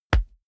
Royalty free sounds: Close combat